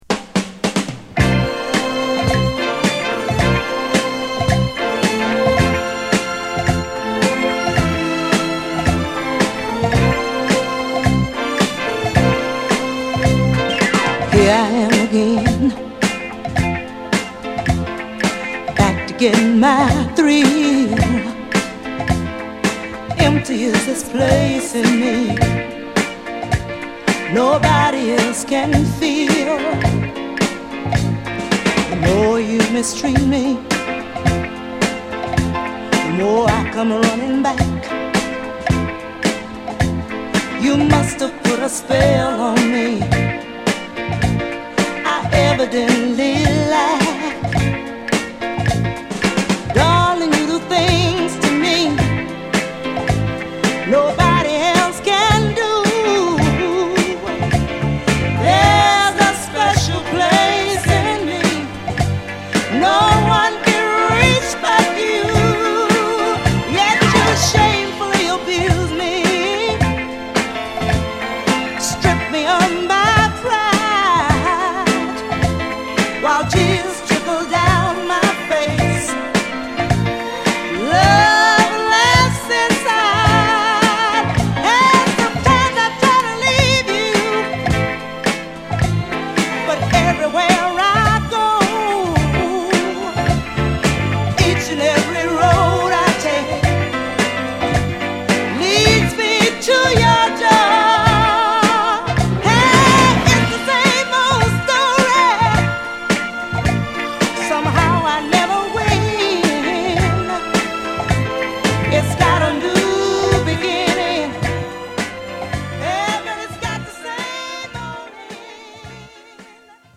アルバム通してソウルフルな仕上がりです！